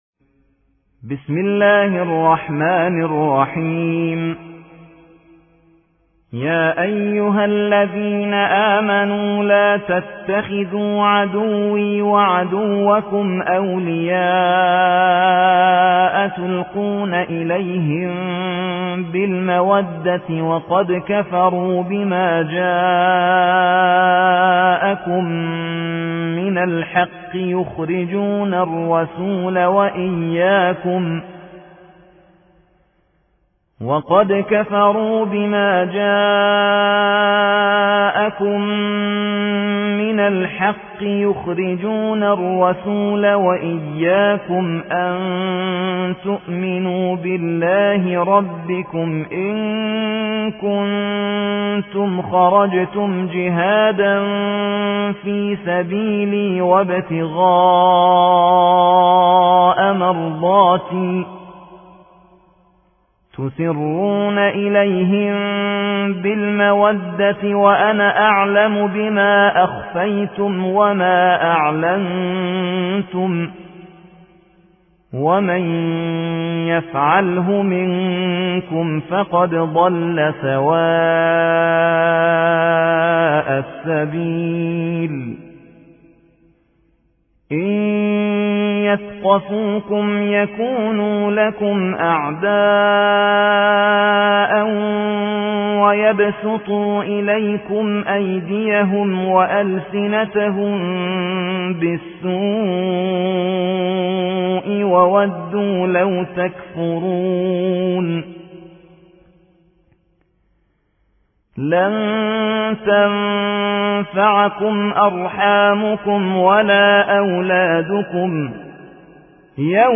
60. سورة الممتحنة / القارئ